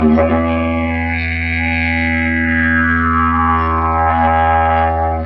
迪吉里杜管 9
用铁三角Pro 70迷你话筒录制。 17年的巨型木制竹迪吉里杜管（由我制作）。
Tag: 90 bpm Ethnic Loops Didgeridoo Loops 906.46 KB wav Key : Unknown